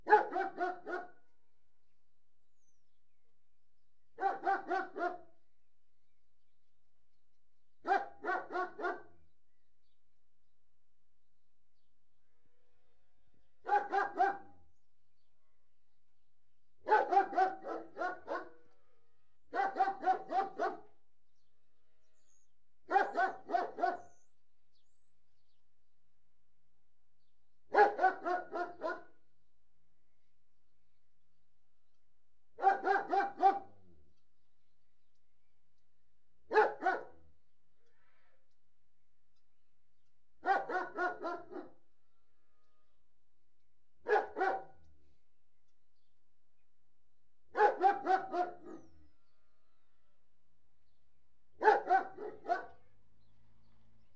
SND_dog_single_rnd_02.ogg